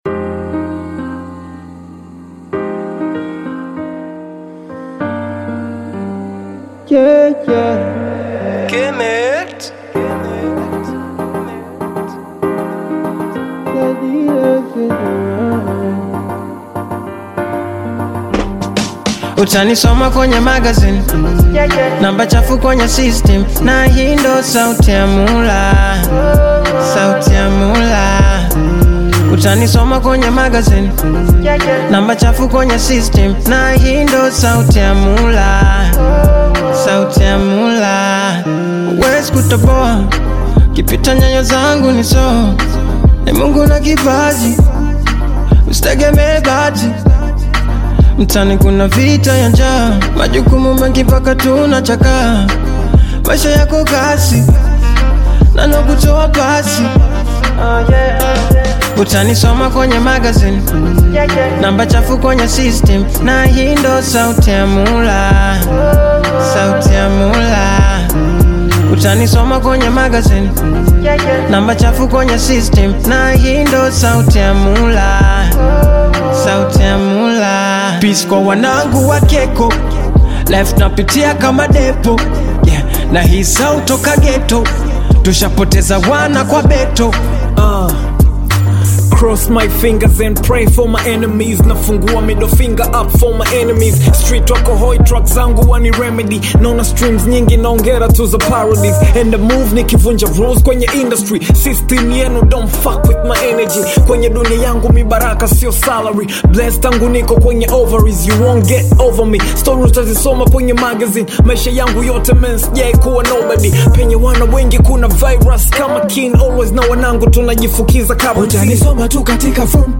Tanzanian Bongo Flava artists
Bongo Flava You may also like